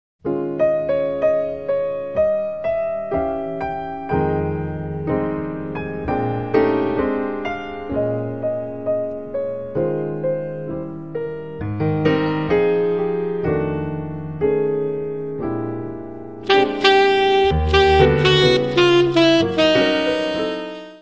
Descrição Física:  1 disco (CD) (53 min.) : stereo; 12 cm
Área:  Jazz / Blues